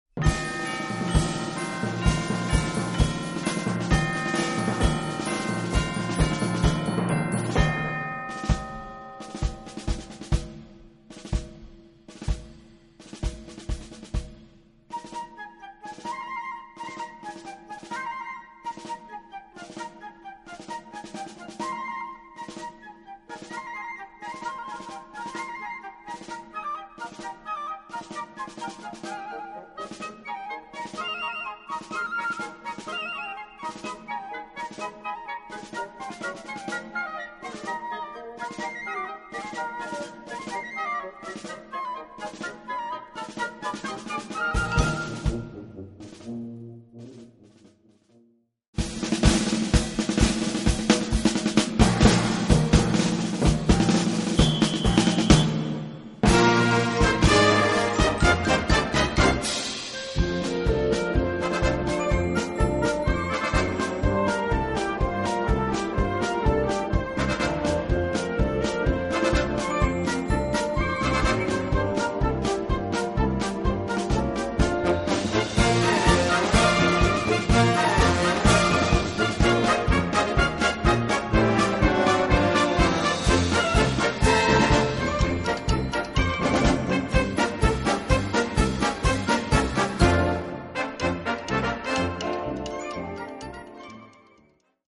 Gattung: Moderne Filmmusik
Besetzung: Blasorchester